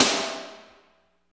55.02 SNR.wav